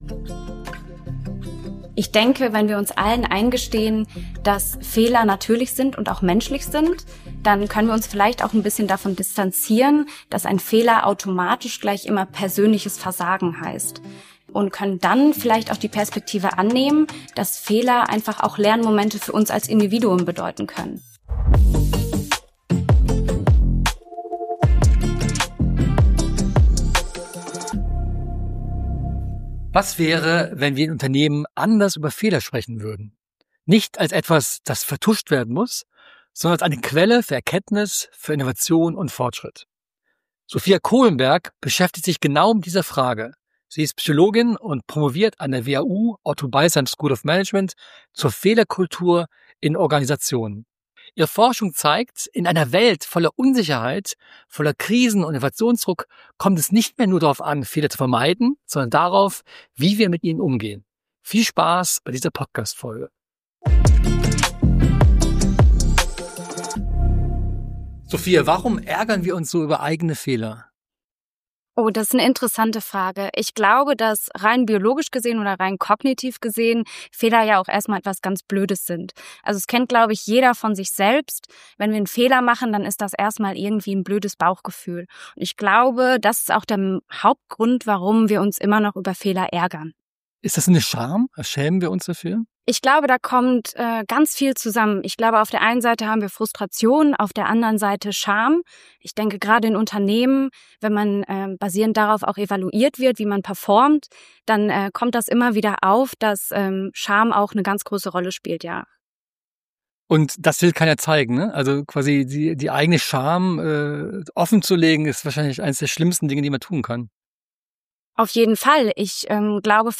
Im Interview erklärt sie, wann Fehler sogar hilfreich sein können und welche Rolle Führungskräfte in einer guten Fehlerkultur spielen.